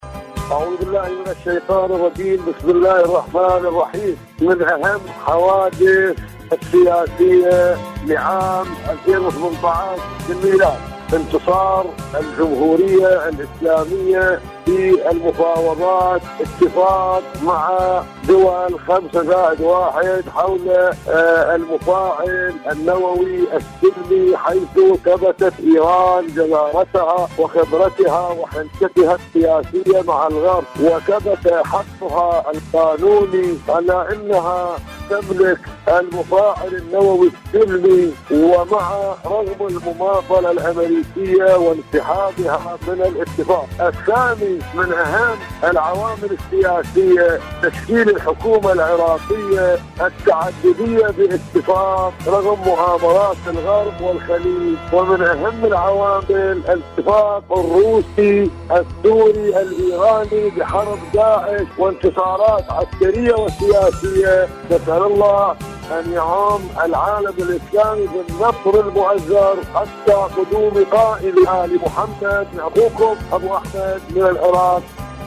برنامج : ألو طهران /مشاركة هاتفية